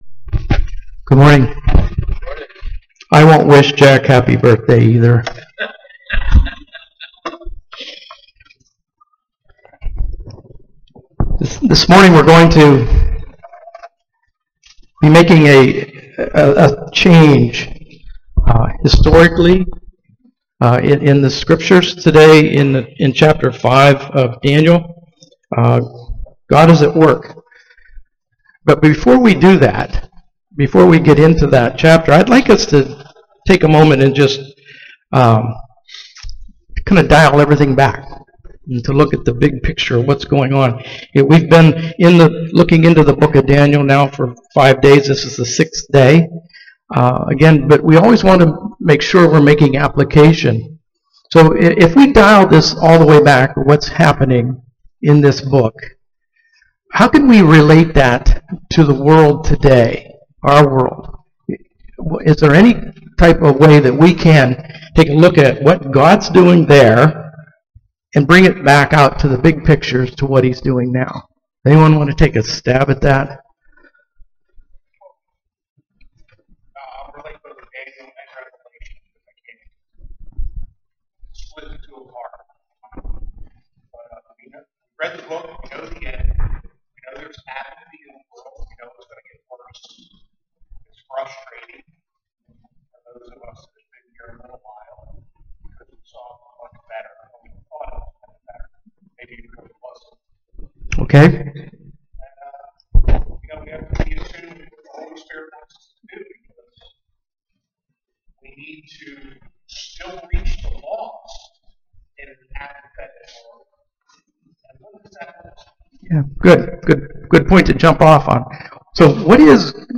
Family Camp 2023